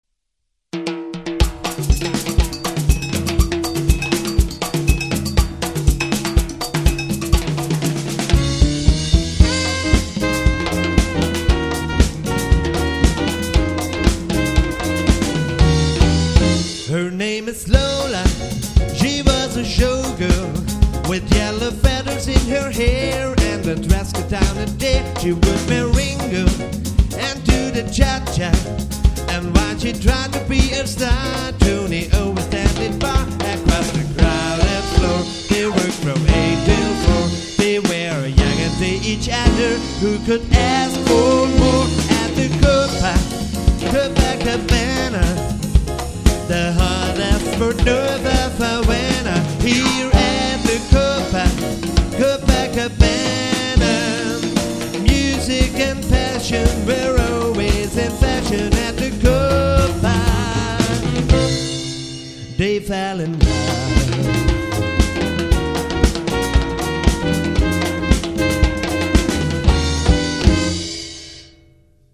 Swing  -  Latin  -  Pop  -  Soul  -  Rock  -  Tanzmusik
Sänger, Saxophon, Piano/Keyboards, Bass und Schlagzeug